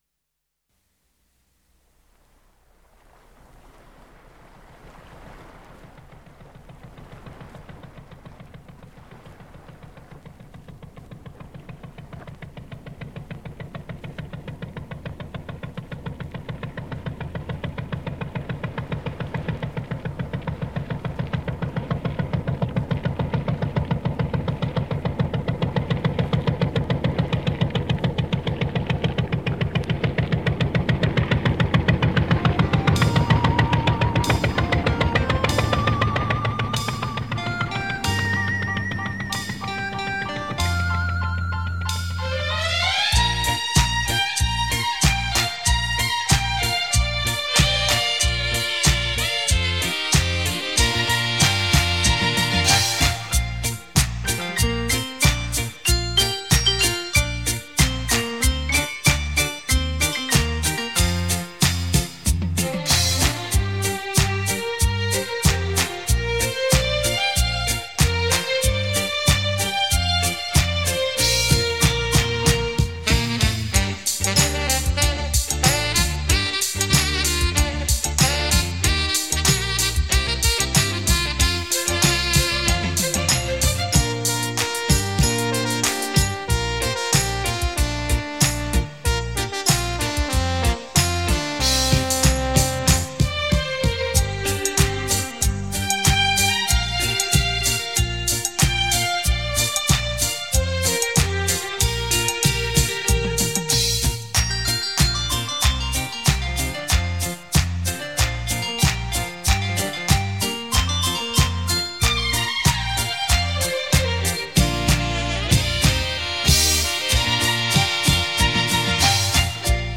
这是一张效果非凡，声场宏大的效果音乐试音碟。
超时空立体音效 百万名琴魅力大出击